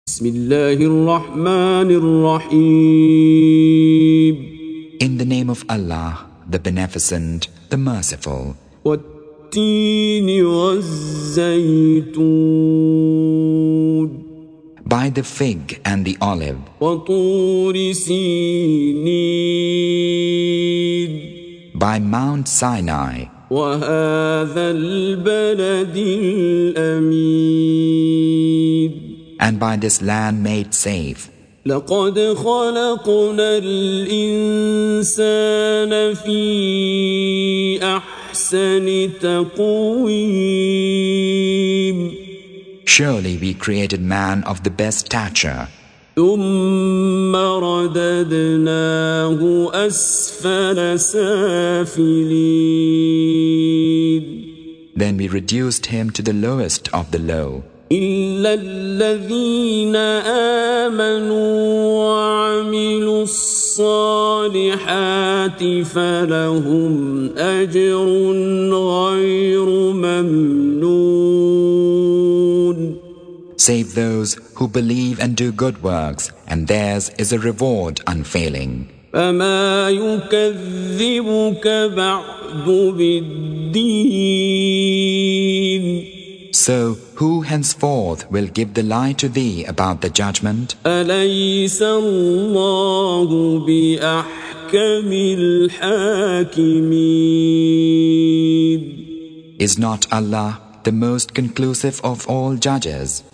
Recitation
Surah Sequence تتابع السورة Download Surah حمّل السورة Reciting Mutarjamah Translation Audio for 95. Surah At-Tin سورة التين N.B *Surah Includes Al-Basmalah Reciters Sequents تتابع التلاوات Reciters Repeats تكرار التلاوات